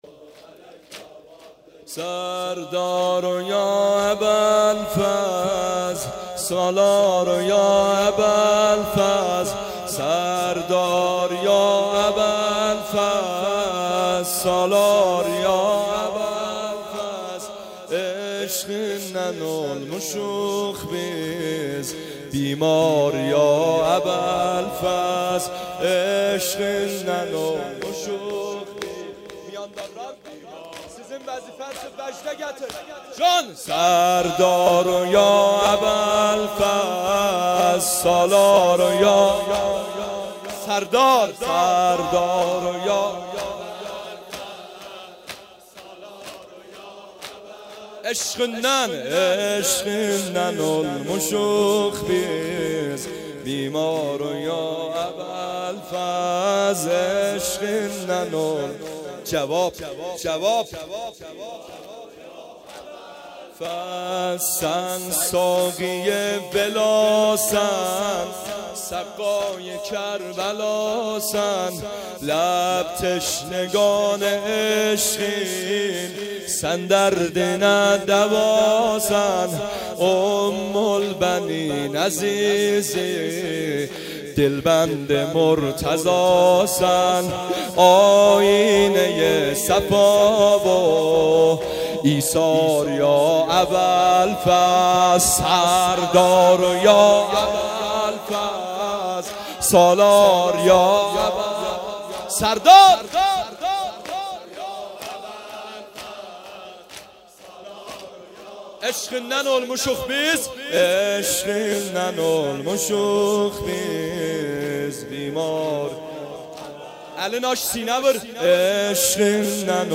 سینه زنی شور | سردار یا ابولفضل
هیئت عاشورائیان زنجان